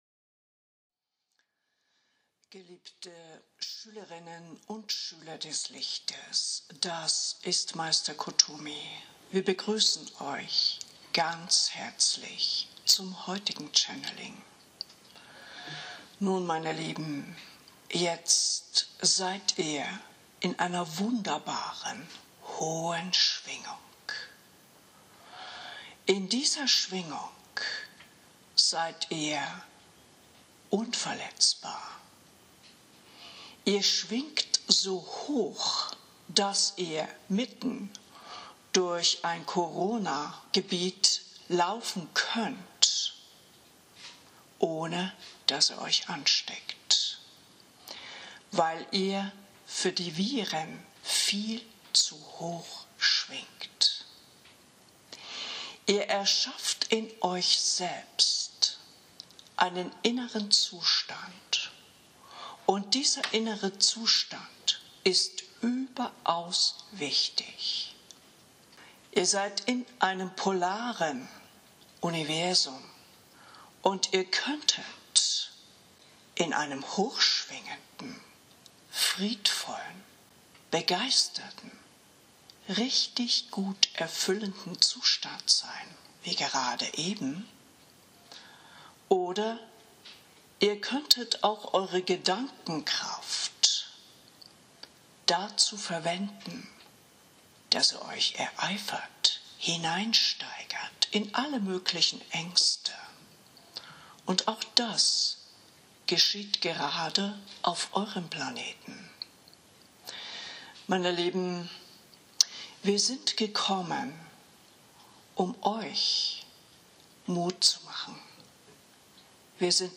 Live-Channeling Meister Kuthumi für 2021